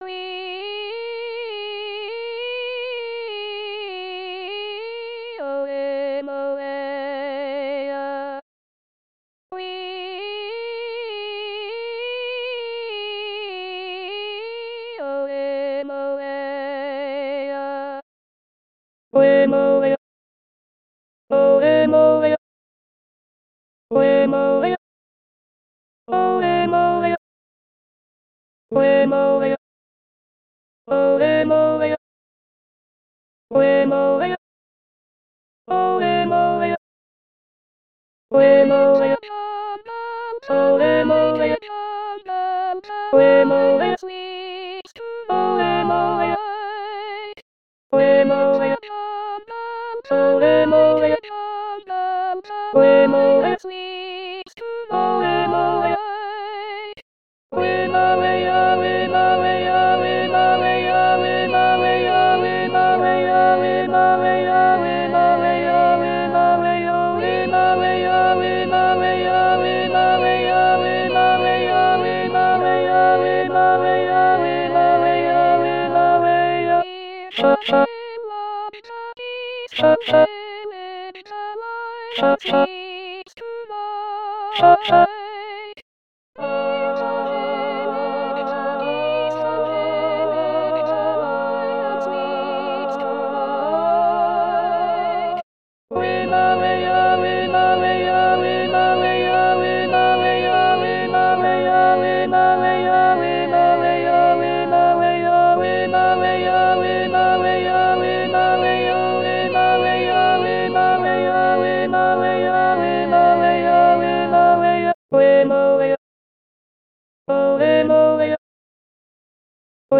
TheLionSleepsV2_Ctda_Contralto1.mp3